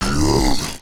death-z.wav